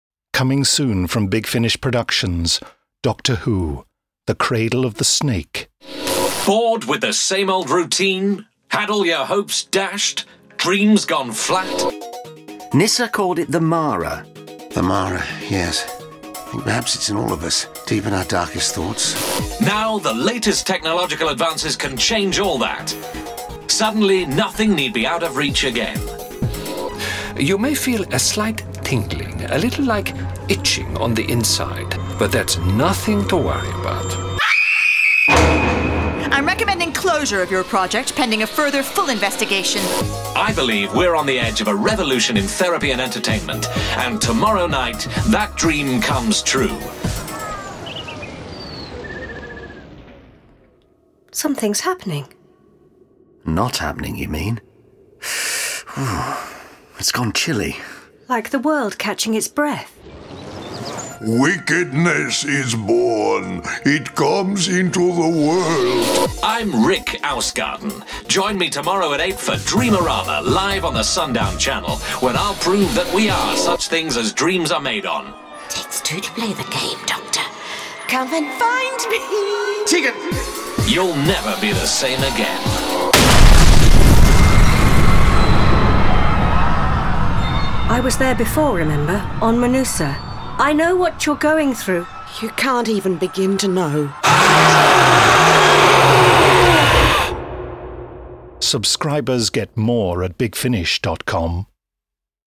full-cast original audio dramas